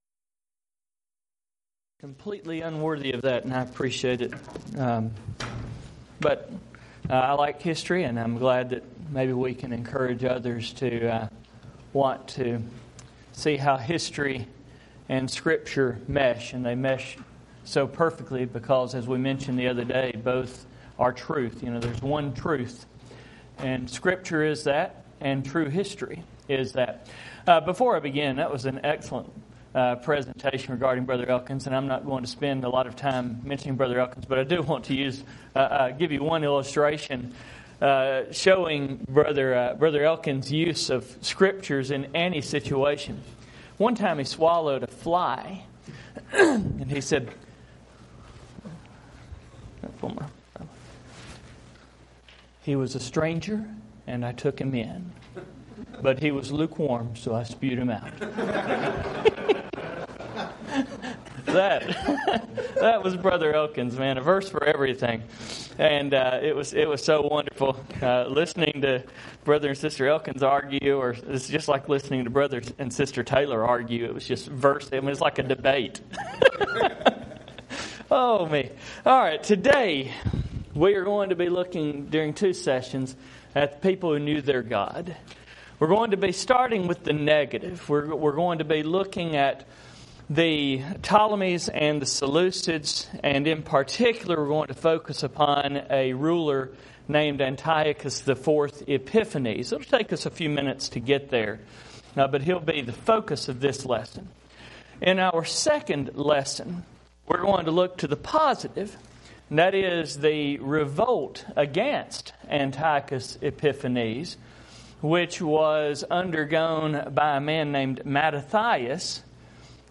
Event: 2017 Focal Point Theme/Title: Preacher's Workshop
lecture